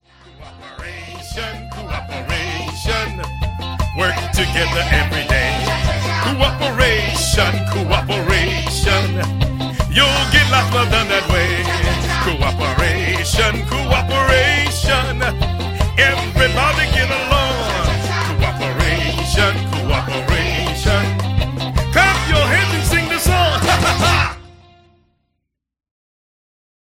• MP3 of both vocals and instrumental